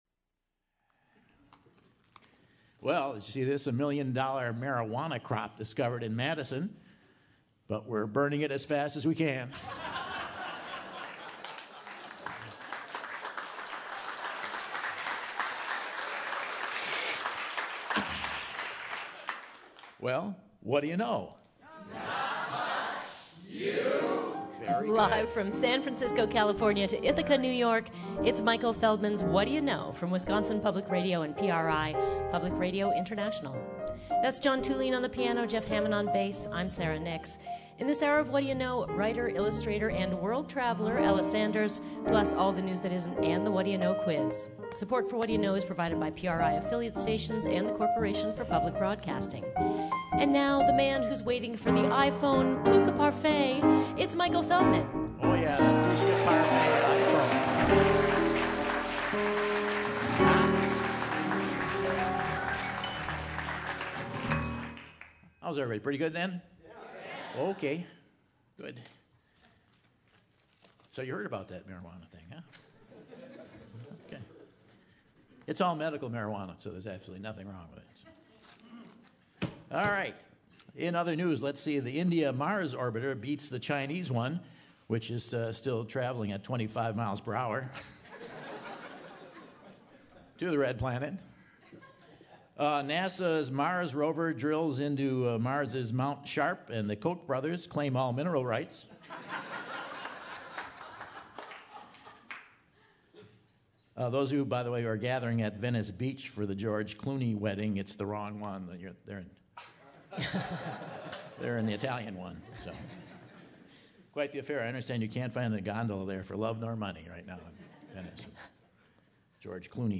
September 27, 2014 - Madison, WI - Monona Terrace | Whad'ya Know?